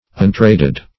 Search Result for " untraded" : The Collaborative International Dictionary of English v.0.48: Untraded \Un*trad"ed\, a. 1.